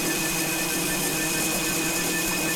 weapon_energy_beam_003_loop.wav